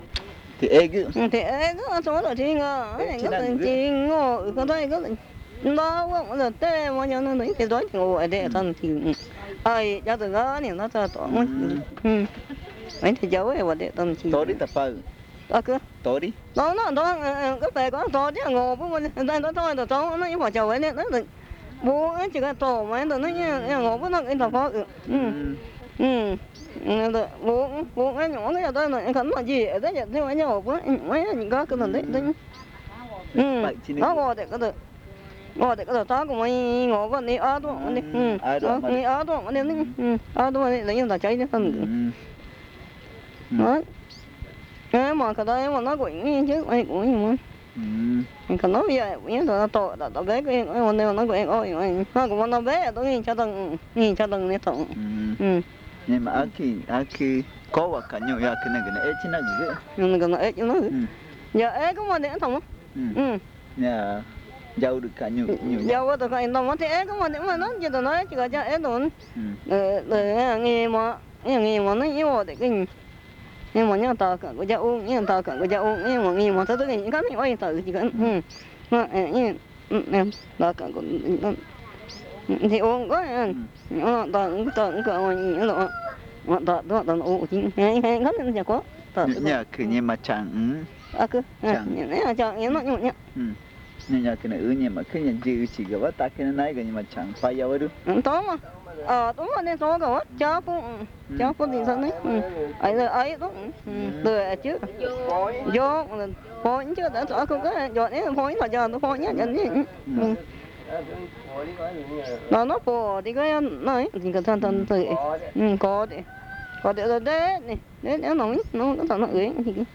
Encuesta léxica y gramatical en Pupuña (Río Cotuhé) - casete 2
Este casete es el segundo de una serie de cuatro casetes grabados en Pupuña.
El audio contiene los lados A y B.